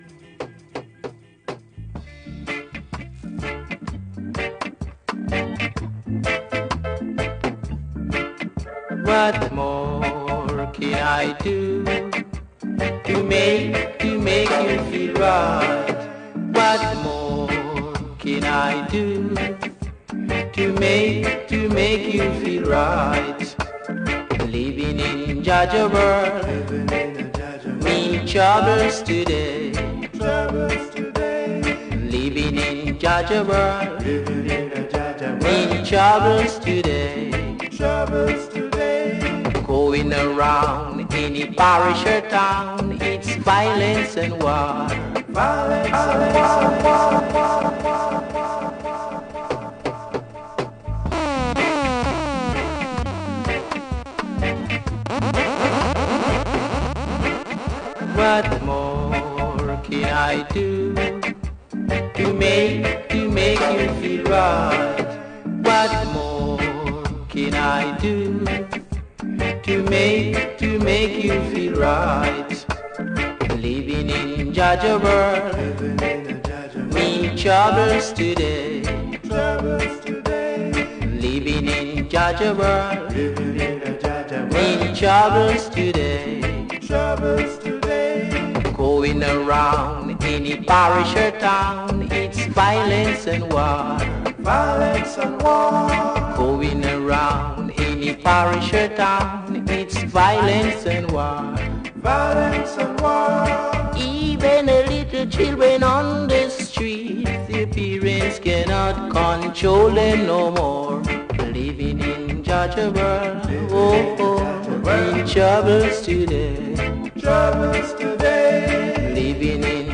NOTES: Facebook muting again = About 30mins edited out!!